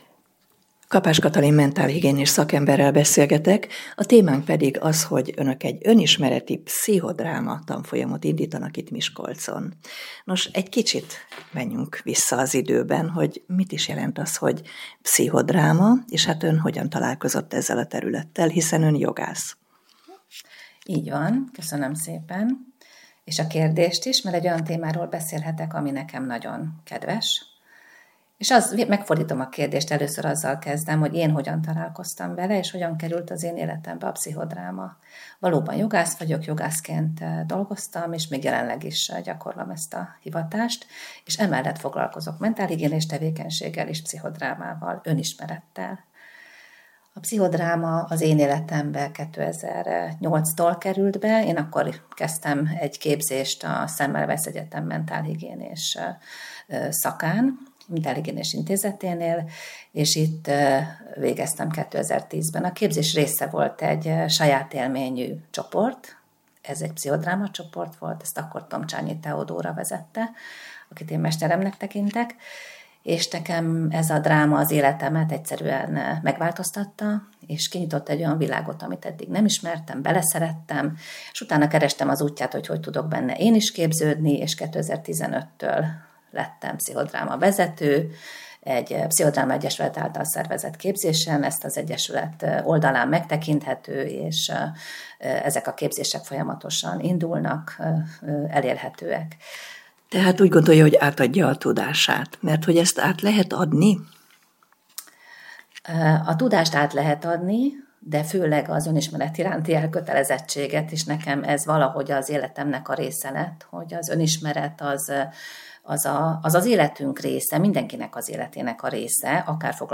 mentálhigiénás szakember volt a Csillagpont Rádió vendége. Arról beszélgettünk, hogy Önismereti-Pszichodráma tanfolyamot inditanak Miskolcon.